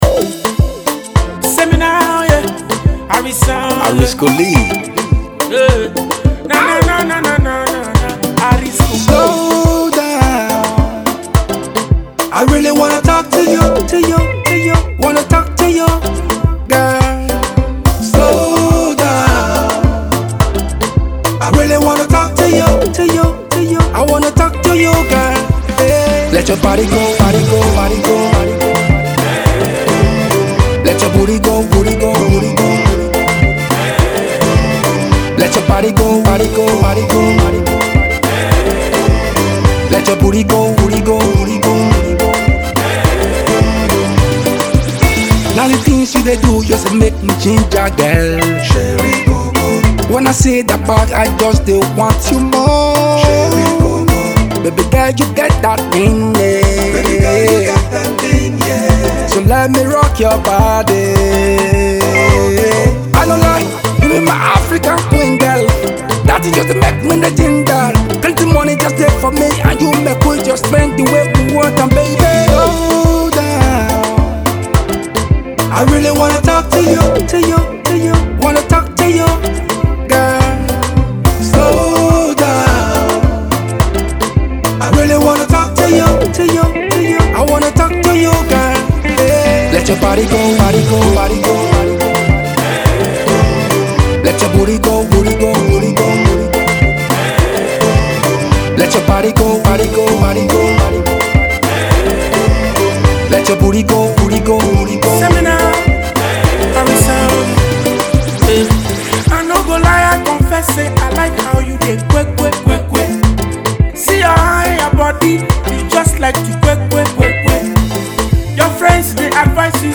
afrobeats jam